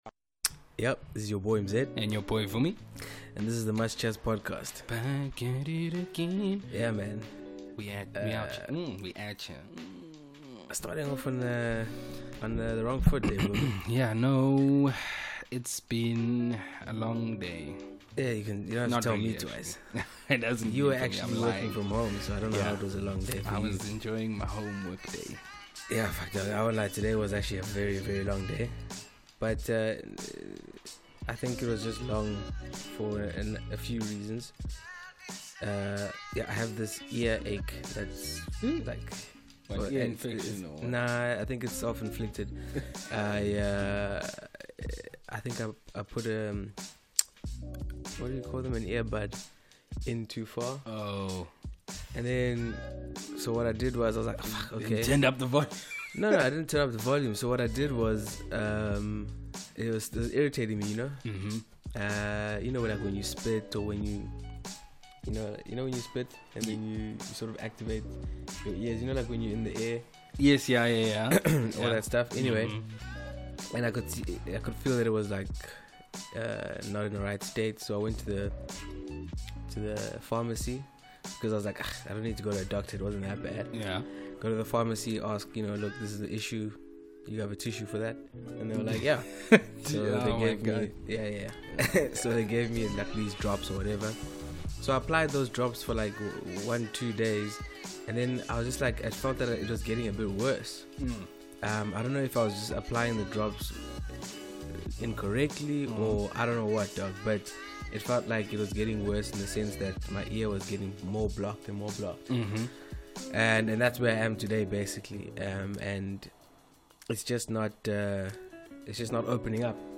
The boys are back and get stuck into the jaded and often toxic world of social media. The boys chat about its effects on their attitudes and on society in general. They also tackle the contreverisial unfolding of the Jussie Smollett saga.